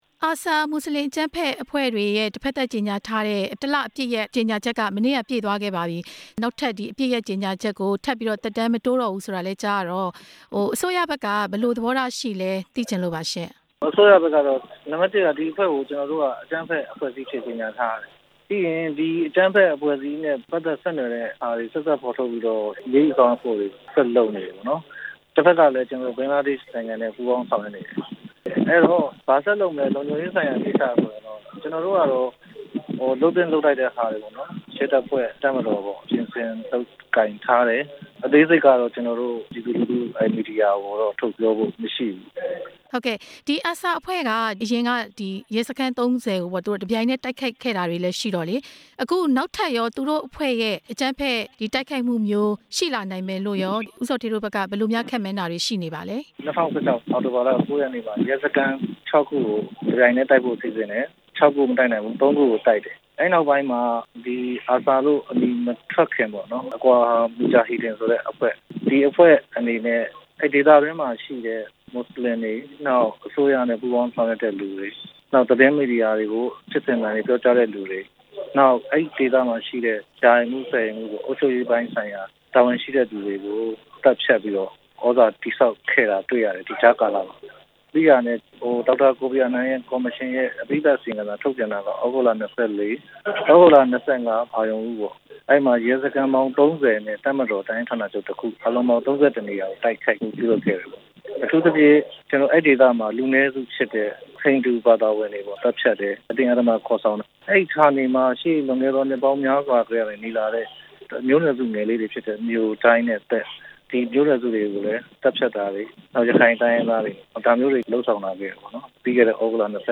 ARSA အဖွဲ့ ဆက်လက်အကြမ်းဖက်နိုင်မှု အလားအလာ မေးမြန်းချက်